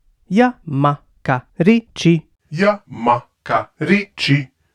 Character Vocals
The first part is the raw recording, and the second part is the final processed sound:
Frog Example
all of the voices are pitch shifted, frequency shifted, and have chorus effects or even phasers on them.
BB_Frog_VoiceExample_01.wav